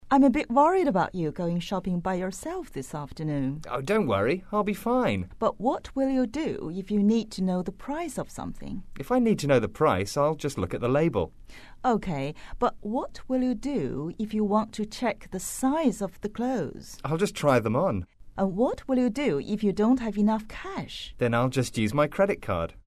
english_37_dialogue_1.mp3